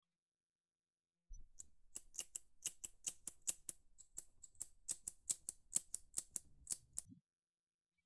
Scissors Cutting Hair
Scissors_cutting_hair.mp3